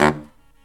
LOHITSAX14-R.wav